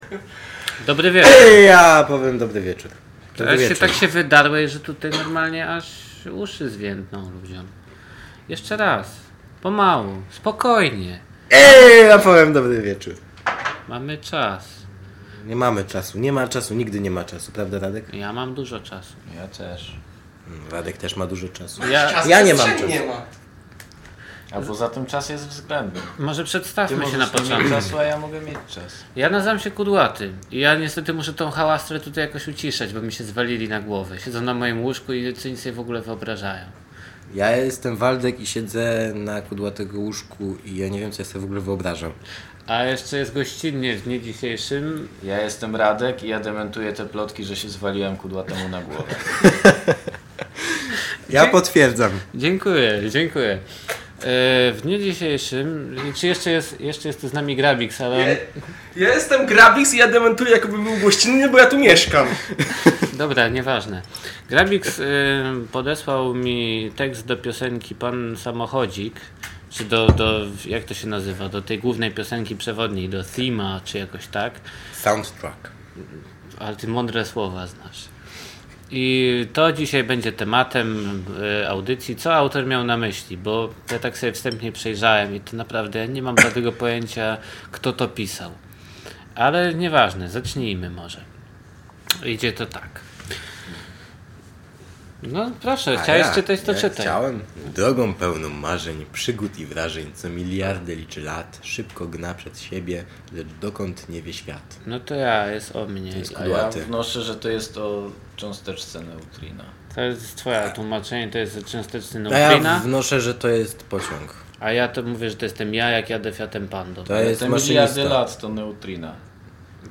Odcinek Bardzo Specjalny, bardzo Chaotyczny, bardzo Wesoły i chyba Głośny... Ale w nim dużo zabawy i dwójka Gości.